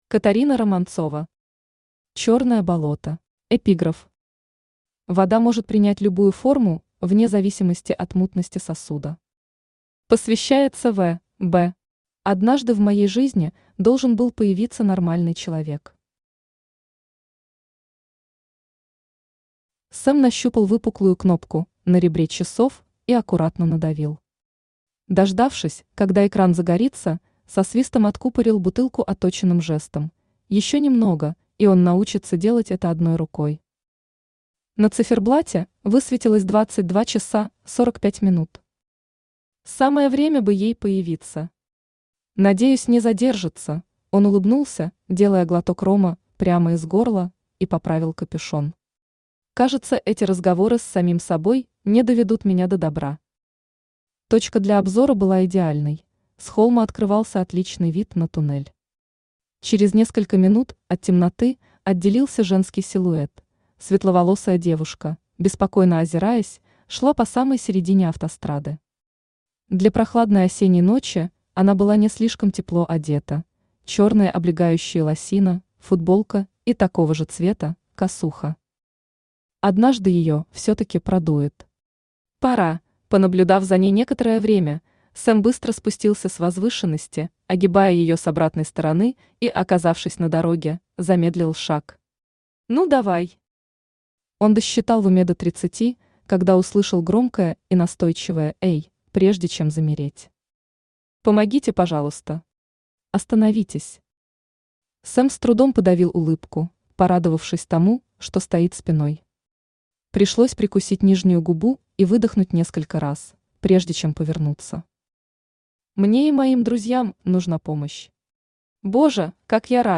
Aудиокнига Черное болото Автор Катарина Романцова Читает аудиокнигу Авточтец ЛитРес.